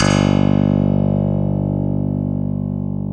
Index of /90_sSampleCDs/Roland - Rhythm Section/KEY_Pop Pianos 2/KEY_MKS20 P3+EP1